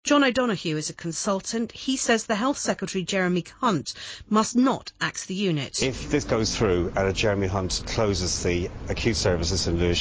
Jeremy Hunt, when Culture Secretary, fell victim to the obvious Spoonerism on broadcast news on several occasions.